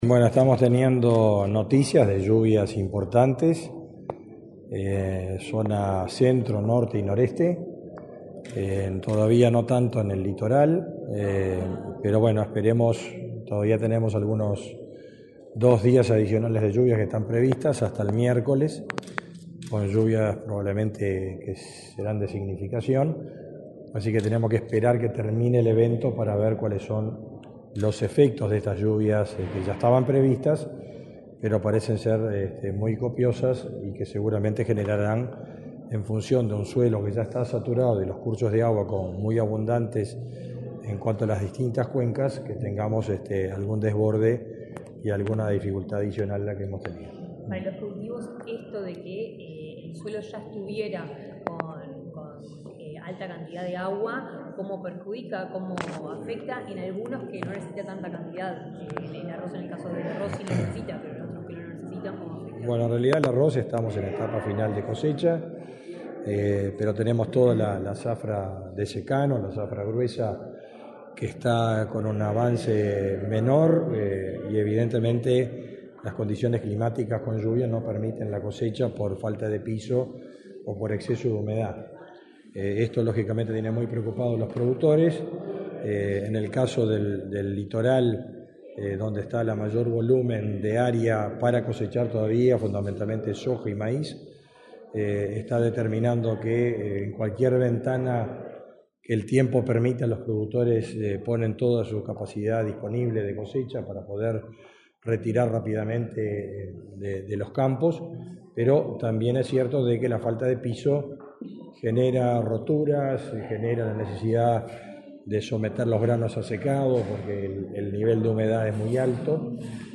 Declaraciones del ministro de Ganadería, Fernando Mattos
Declaraciones del ministro de Ganadería, Fernando Mattos 06/05/2024 Compartir Facebook X Copiar enlace WhatsApp LinkedIn Este lunes 6 en la Torre Ejecutiva, el ministro de Ganadería, Fernando Mattos, dialogó con la prensa, luego de participar en el acto en el que se realizó un balance de la temporada de incendios forestales y de campo.